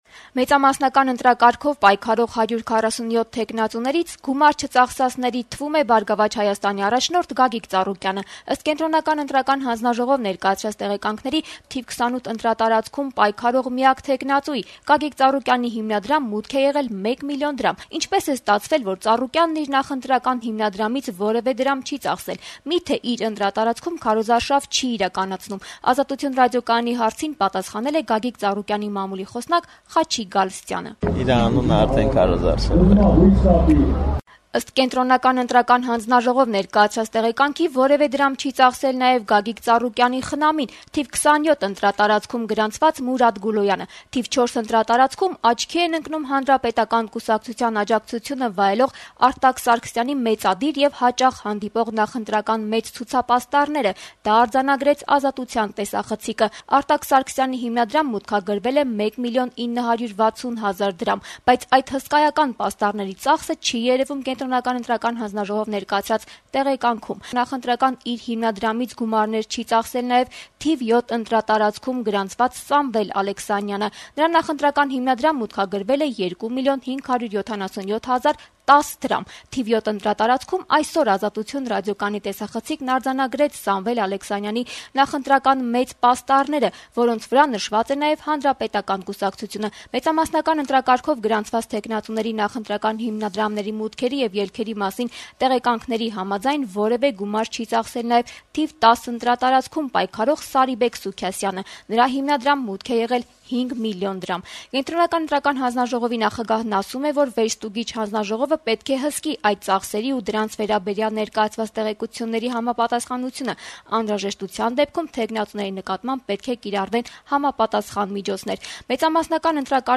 Այս մասին այսօր «Ազատություն» TV-ի ուղիղ եթերում ասաց Հանրապետական կուսակցության փոխնախագահ Գալուստ Սահակյանը։